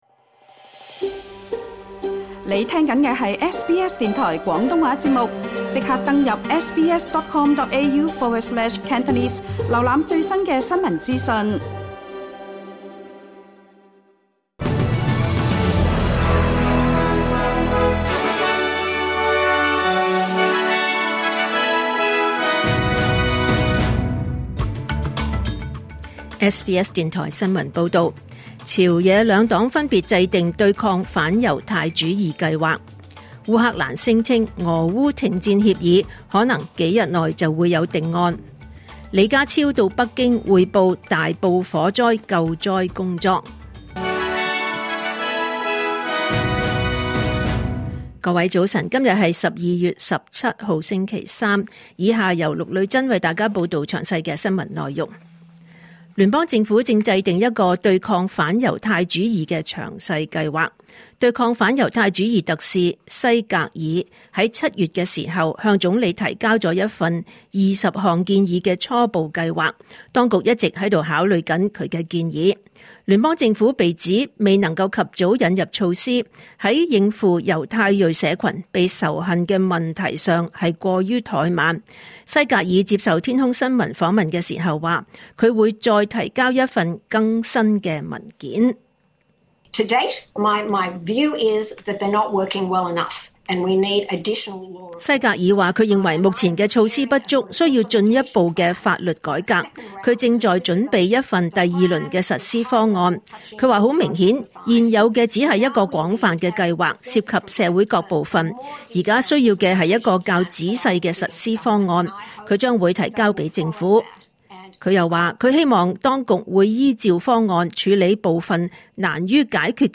2025年12月17日SBS廣東話節目九點半新聞報道。